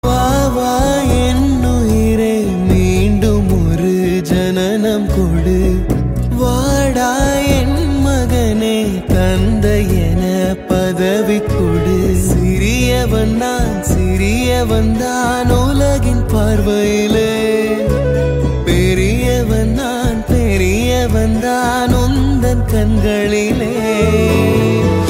Best Ringtones, Tamil Ringtones